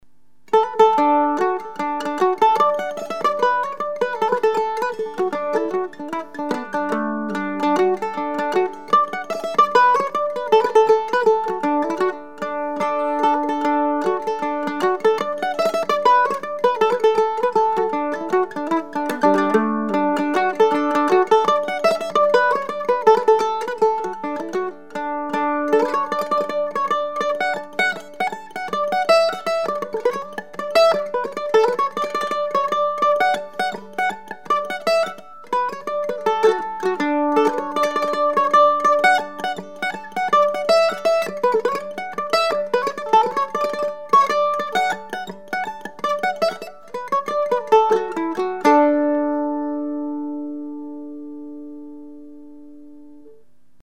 Classical Flattop Mandolin #341  $3200 (includes case)
The top is about 30% lighter and gives more volume and improved tone.
A clean, clear evenly balanced sound.